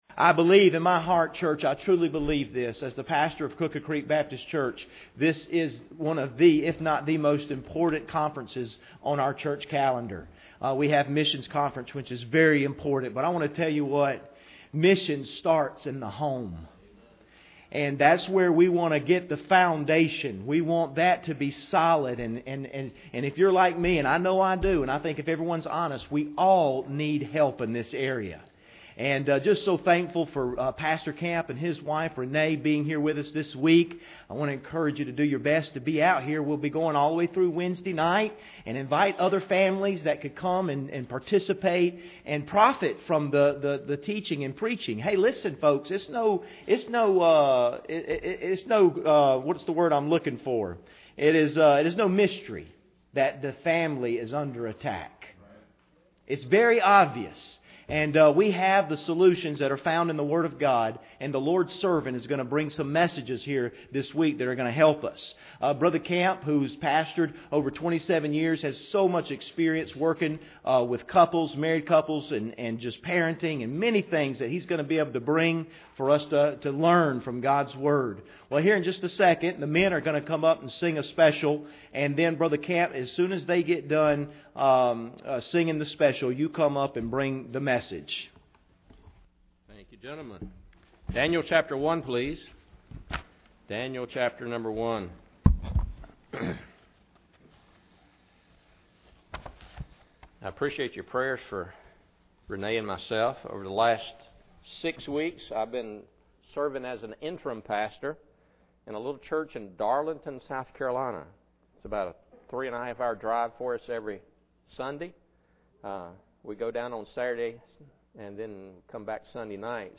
Passage: Daniel 1:1-8 Service Type: Sunday Morning Bible Text